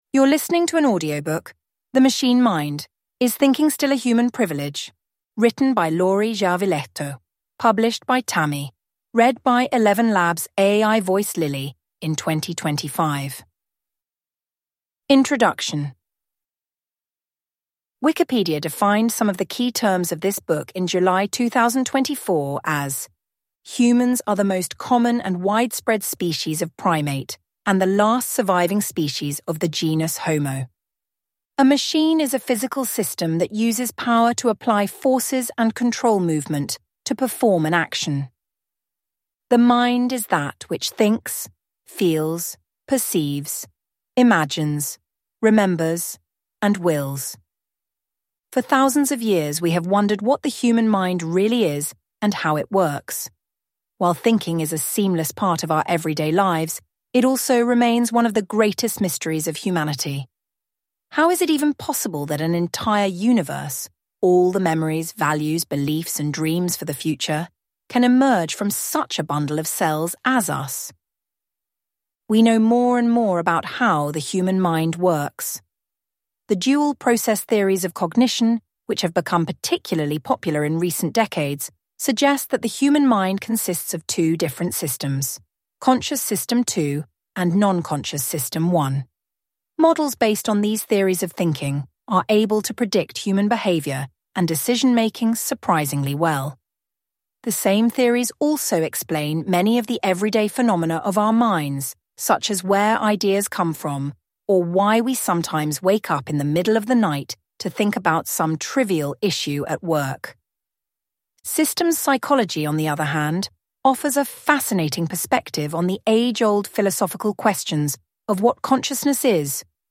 The Machine Mind (ljudbok) av Lauri Järvilehto